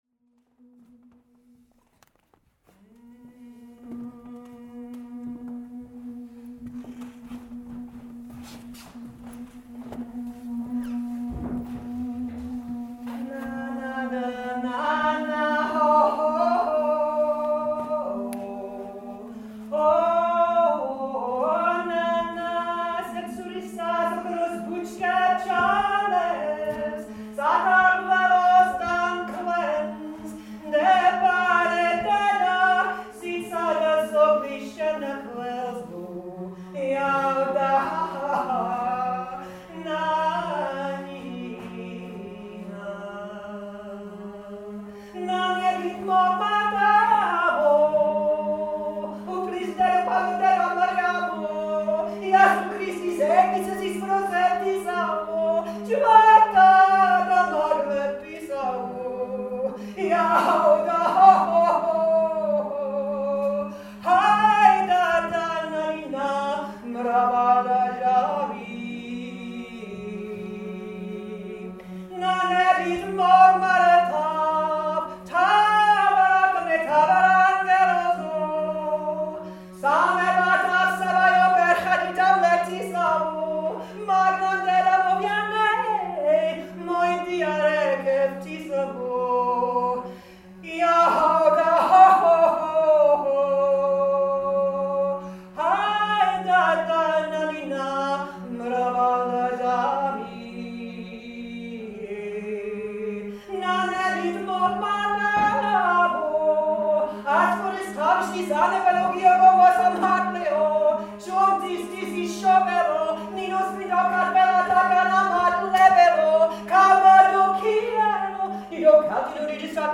Nanebit is a Georgian supplication of the Mother Goddess to open the gates of heaven. It would be sung so that the soul could depart.